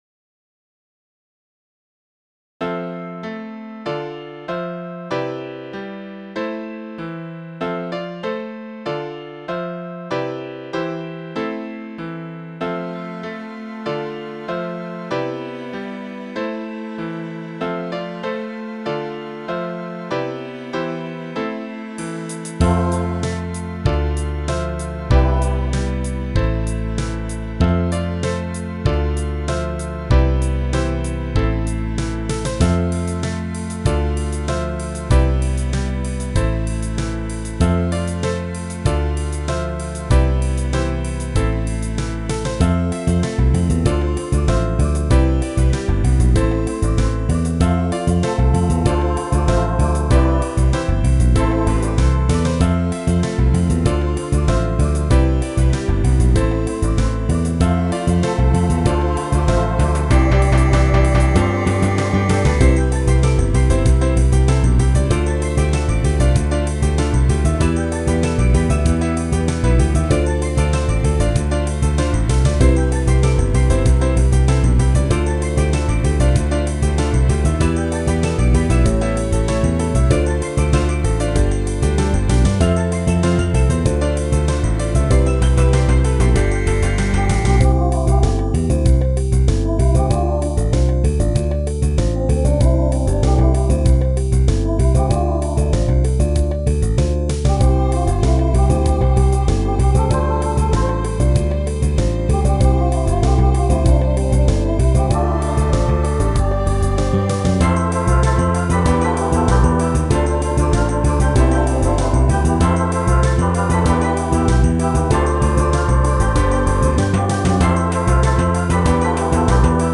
これもバンド用。